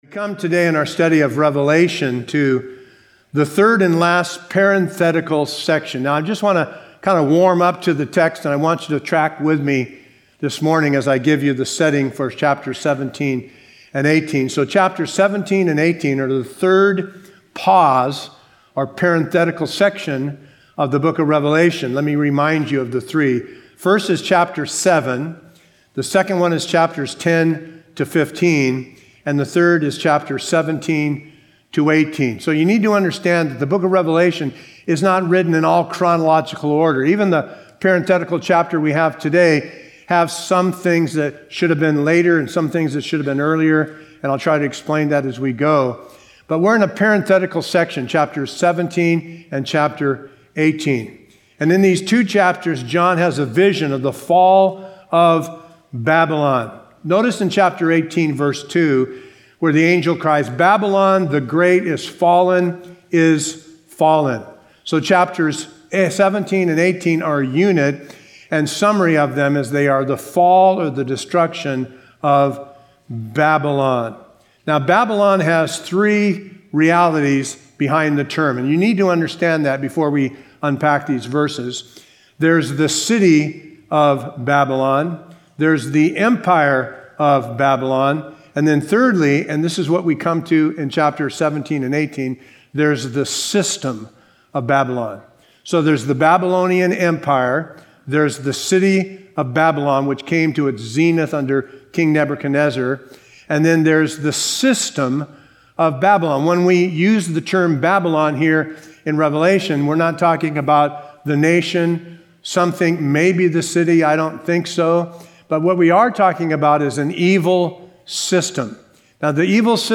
A verse-by-verse expository sermon